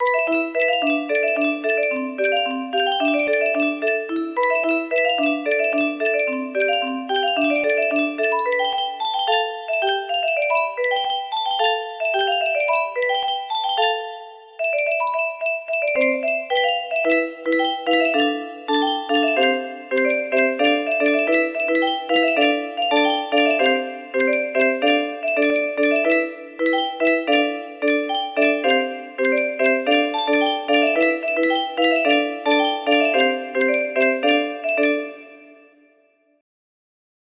1:31-2:14 music box melody